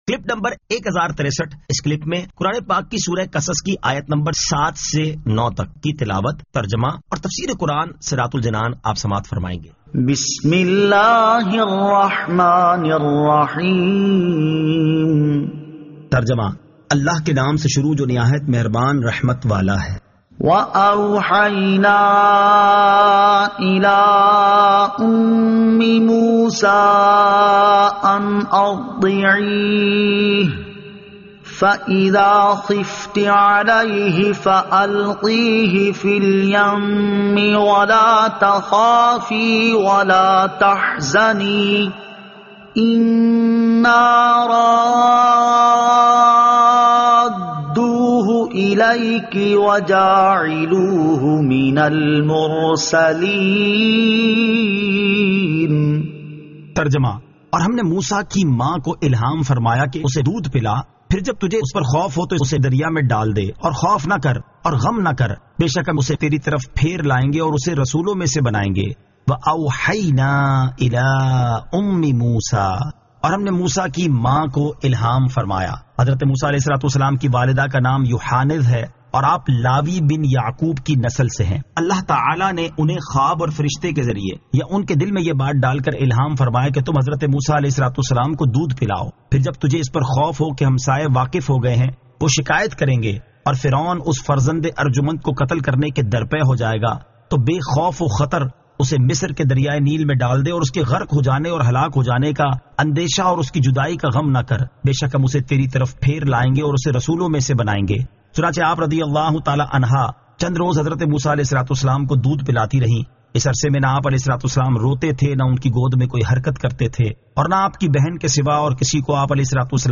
Surah Al-Qasas 07 To 09 Tilawat , Tarjama , Tafseer